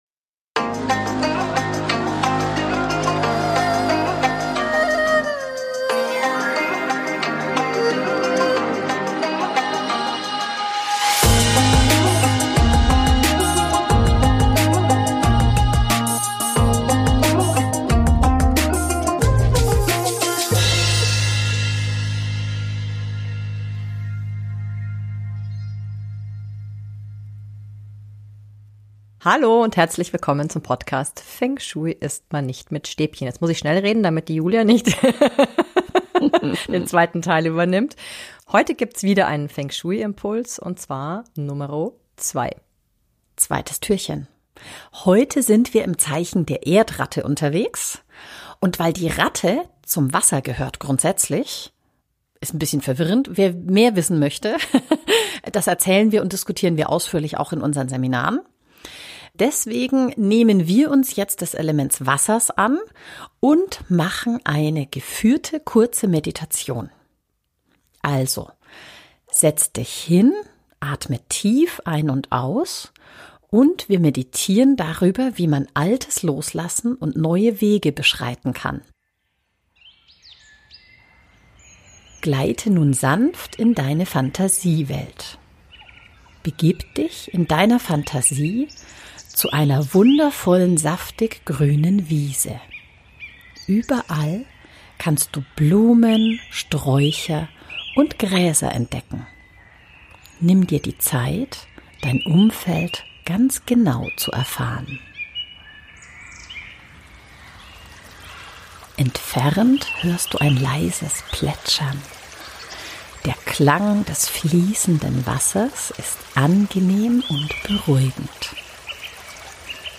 Meditation "Altes loslassen und neue Wege gehen"